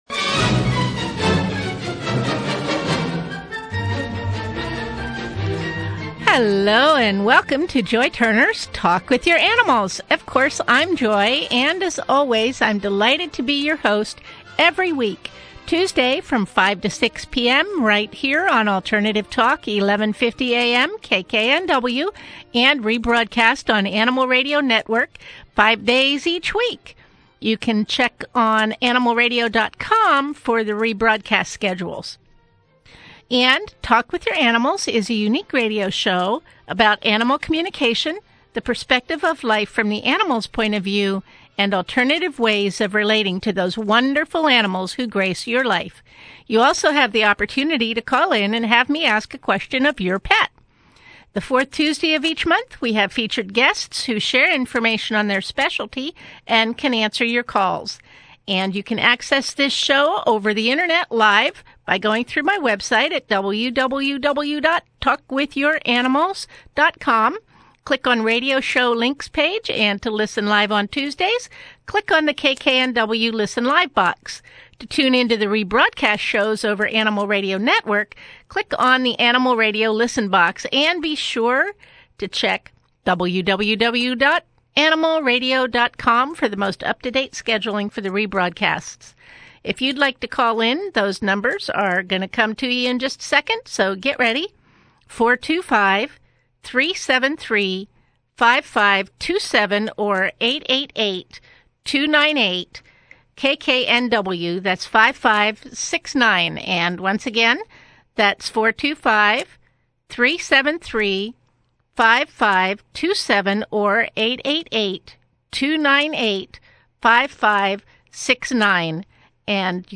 Radio Show, Seattle Talk With Your Animals February 21